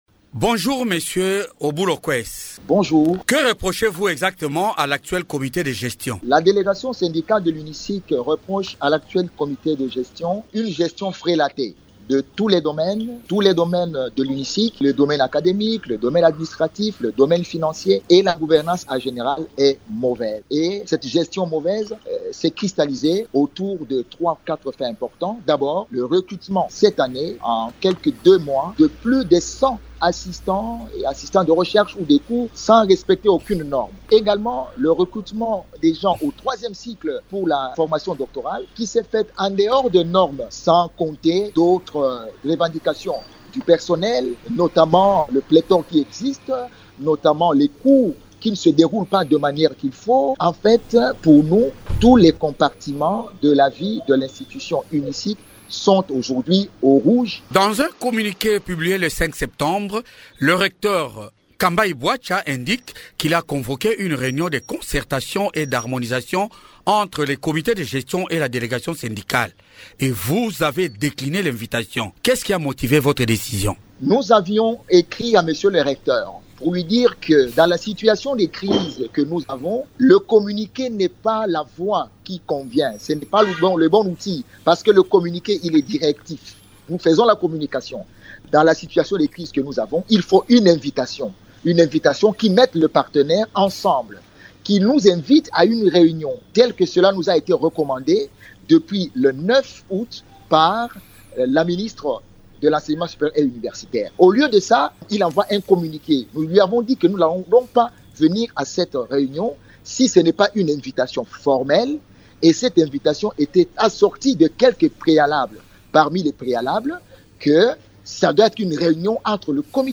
L'invité du jour, Émissions / milice, Appel de la paix, Ituri, Djugu, le général Antoine David Mushimba